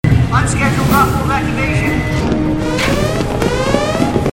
Gate_alarm.mp3